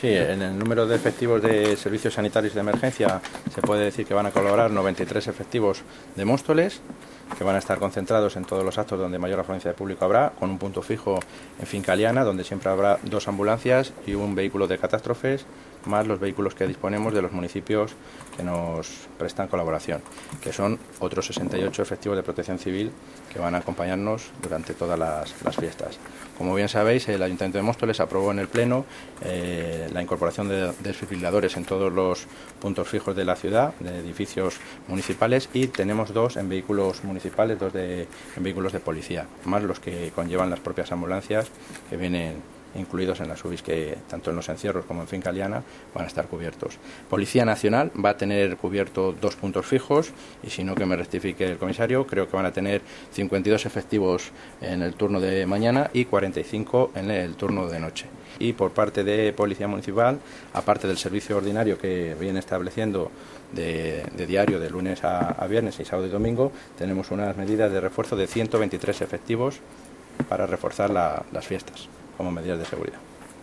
Audio - Roberto Sánchez (Concejal de Presidencia, Seguridad Ciudadana, Movilidad y Comunicación)